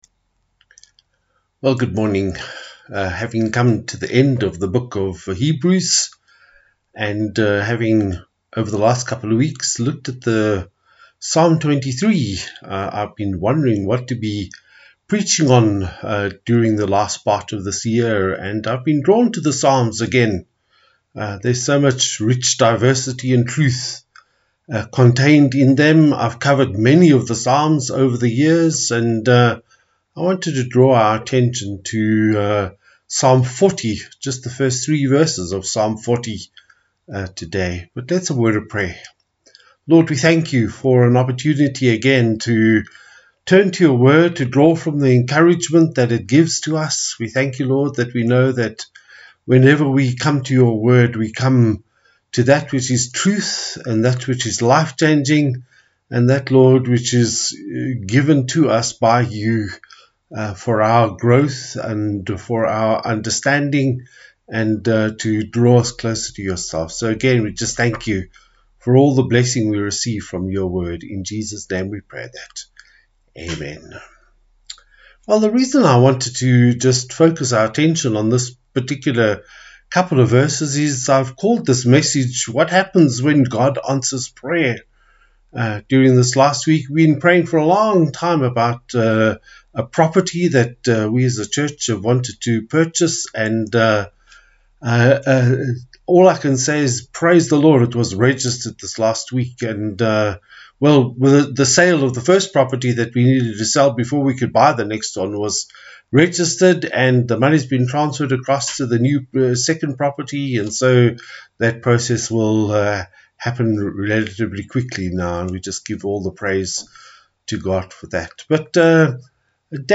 Passage: Psalm 40:1-3 Service Type: Sunday Service